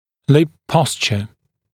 [lɪp ‘pɔsʧə][лип ‘посчэ]положение губ